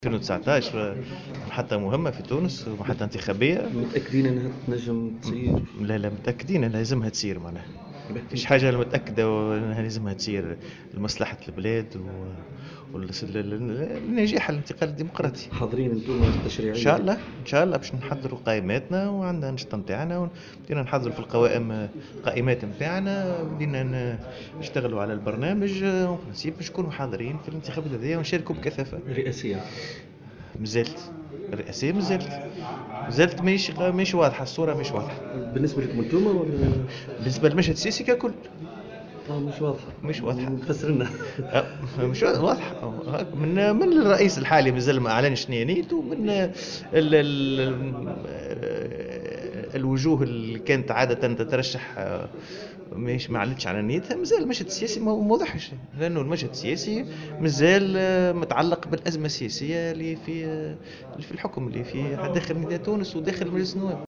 ولاحظ في تصريح لمراسل "الجوهرة اف أم" على هامش ندوة نظمها الحزب في المنستير حول قانون المالية، أن الانتخابات الرئاسية في المقابل مازالت غير واضحة بسبب المشهد السياسي المتأزم في تونس، وفق تعبيره.